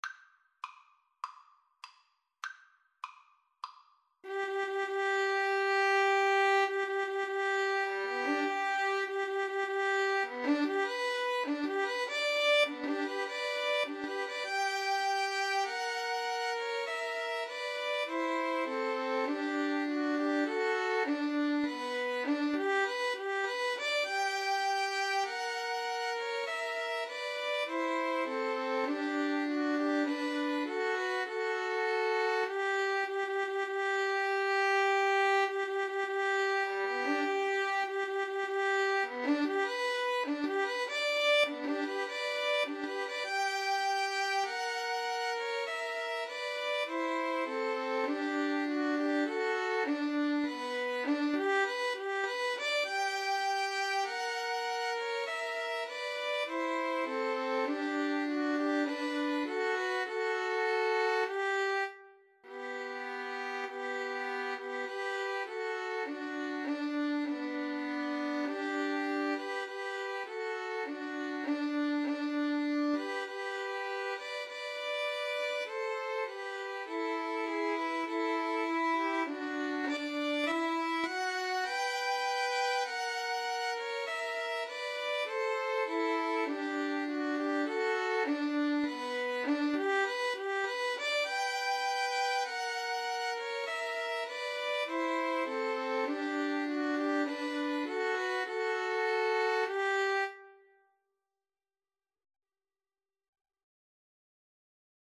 Andante maestoso =100
Violin Trio  (View more Easy Violin Trio Music)
Classical (View more Classical Violin Trio Music)